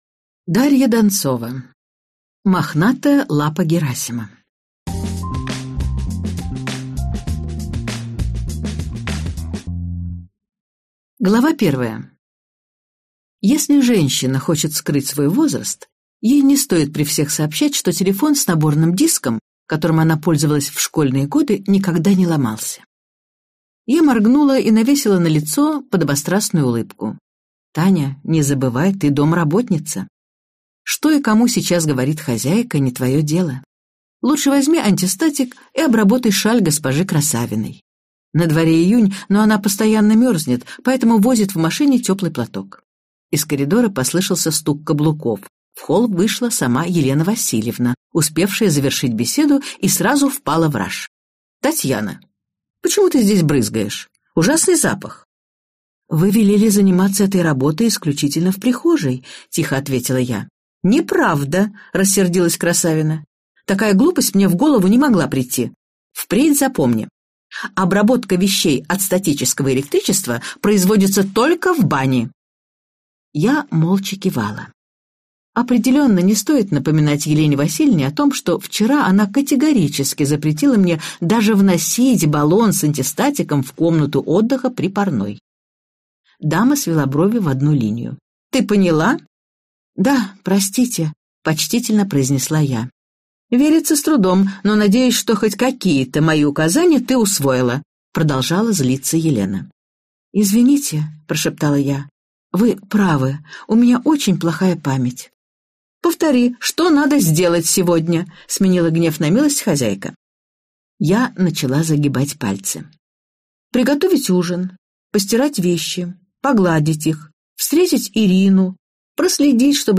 Аудиокнига Мохнатая лапа Герасима - купить, скачать и слушать онлайн | КнигоПоиск